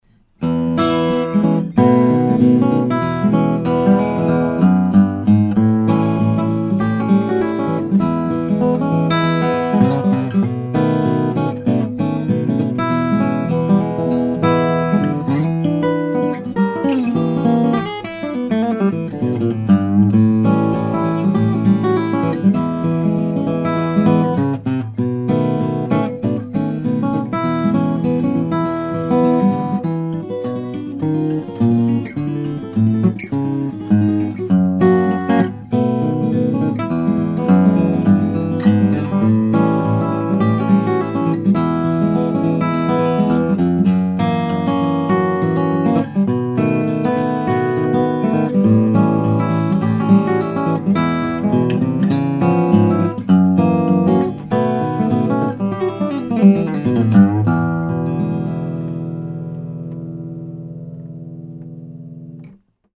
The 12 Fret Slothead design creates that distinctive 000 sound by putting the bridge in the widest part of the bout where can impart it’s power to the fullest. The slotted headstock increases the decay of the notes slightly, so the notes get out of your way for style such as blues and rags.